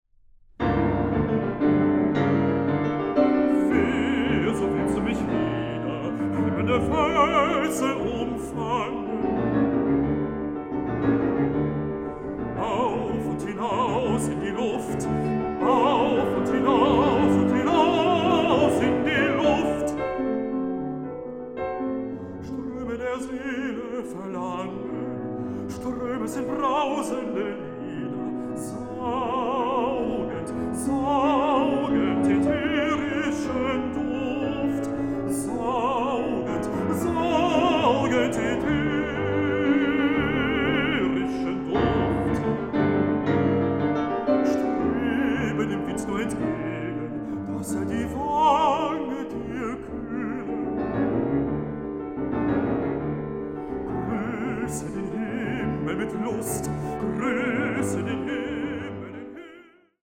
Recording: Mendelssohn-Saal, Gewandhaus Leipzig, 2025